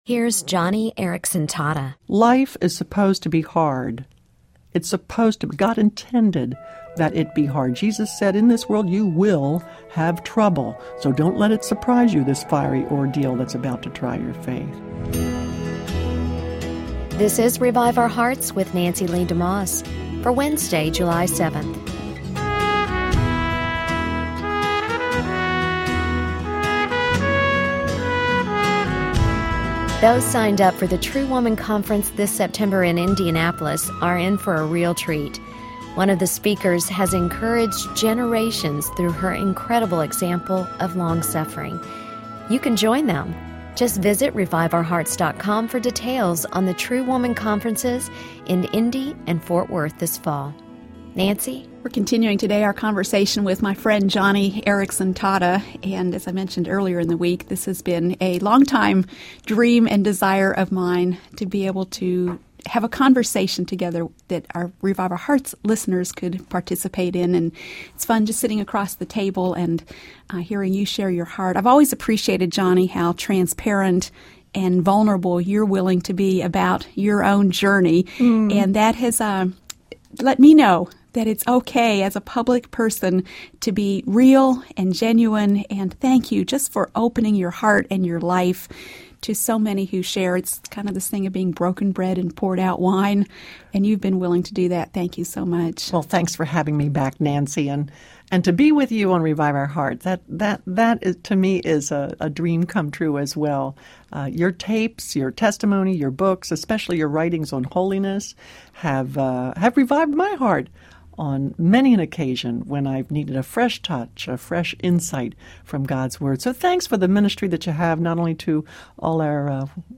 A Conversation with Joni Eareckson Tada